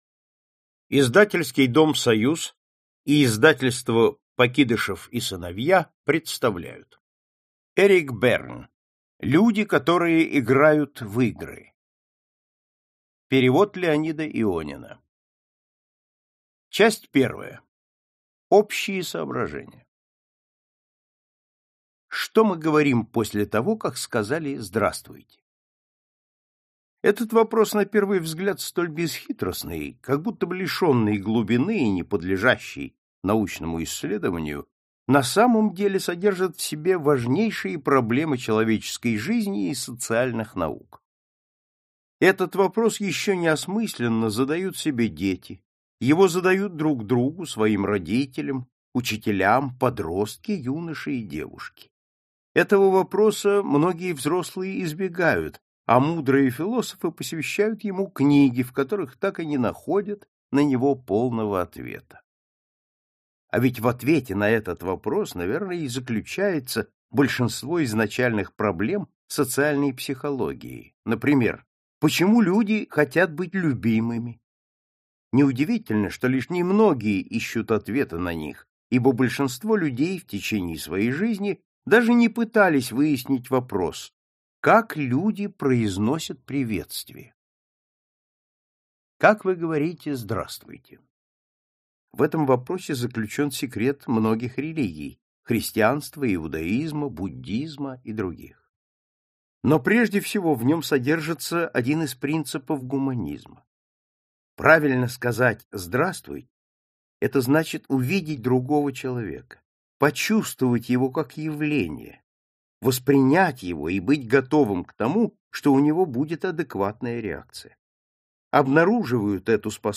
Аудиокнига Люди, которые играют в игры | Библиотека аудиокниг